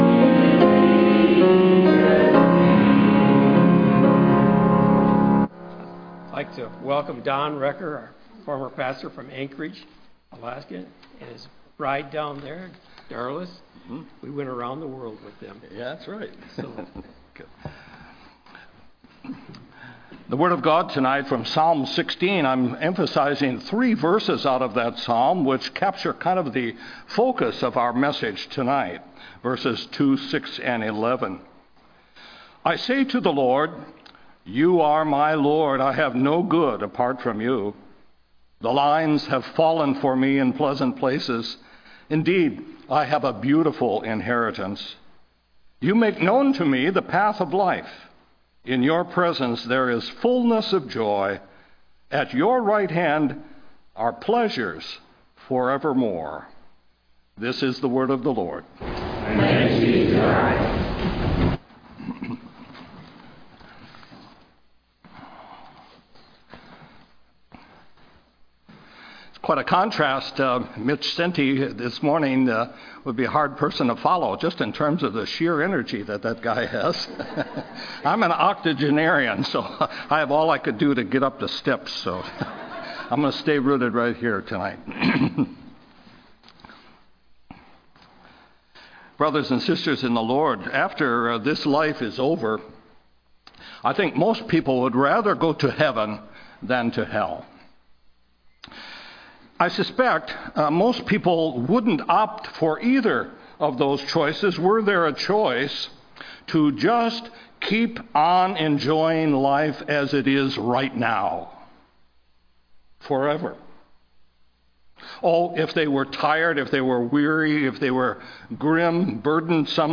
Text for Sermon: II Samuel 21:1-14; John 19:235-25